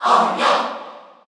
Category: Crowd cheers (SSBU) You cannot overwrite this file.
Incineroar_Cheer_Korean_SSBU.ogg